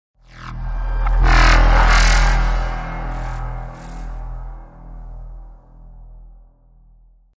Здесь вы найдете плавные затухания, исчезающие эффекты и другие варианты финальных аудиоштрихов.